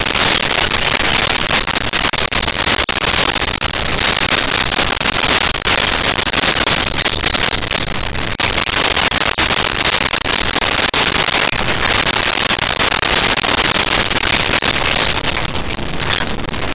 Rat Squeaks Movement
Rat Squeaks Movement.wav